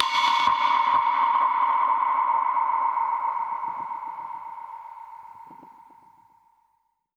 Index of /musicradar/dub-percussion-samples/134bpm
DPFX_PercHit_A_134-03.wav